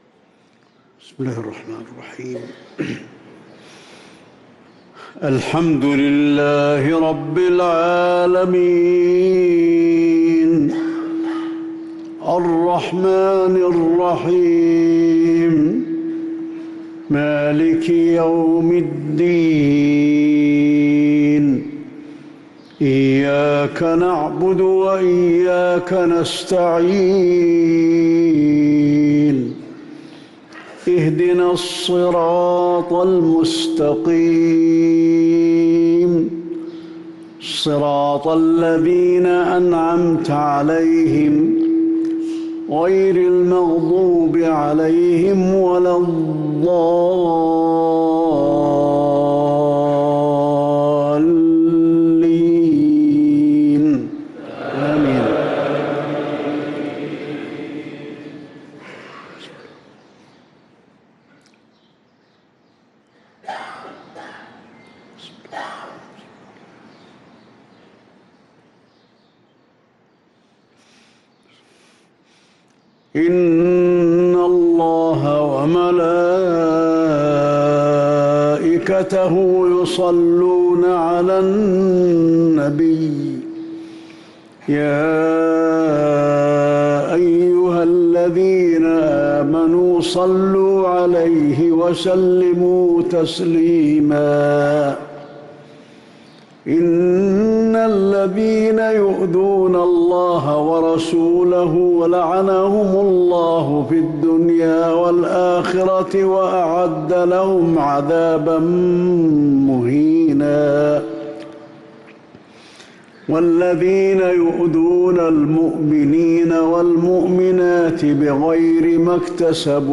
صلاة المغرب للقارئ علي الحذيفي 20 ربيع الأول 1445 هـ
تِلَاوَات الْحَرَمَيْن .